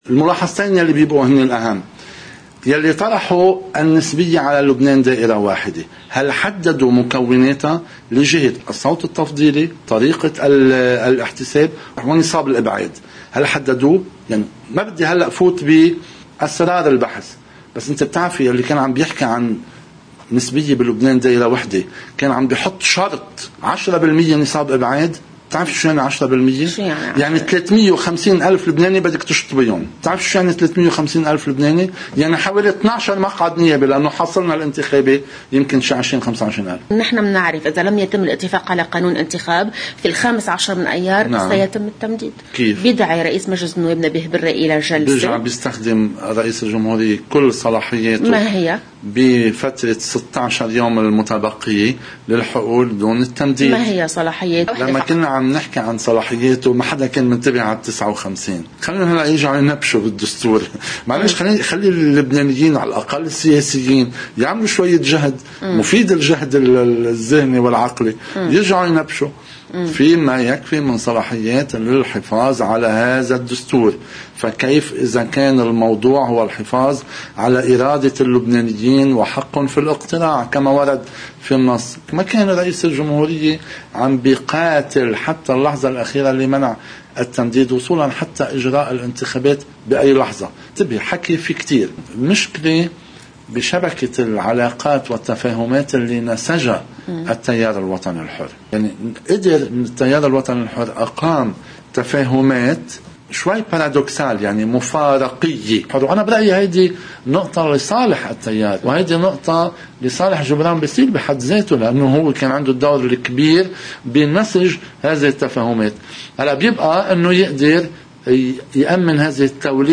مقتطف من حديث مستشار الإعلامي لرئاسة الجمهورية جان عزيز لقناة الـ”OTV”: